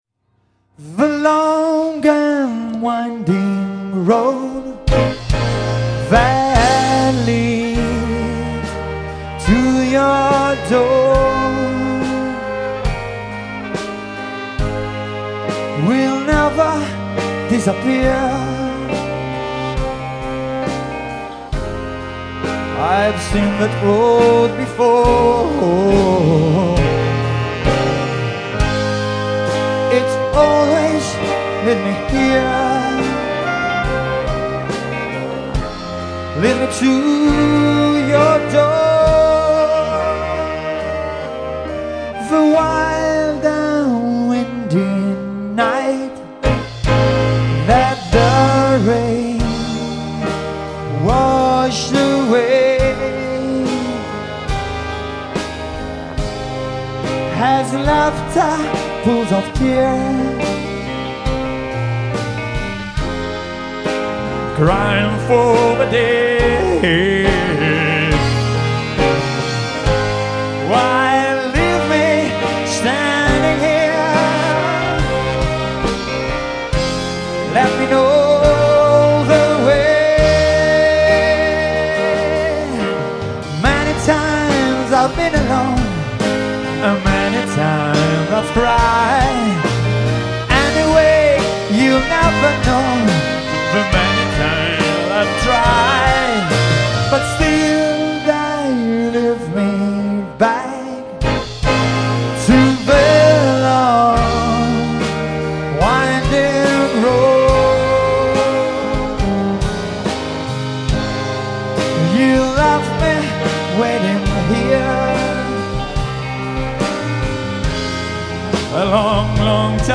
live with brass band @ Arcene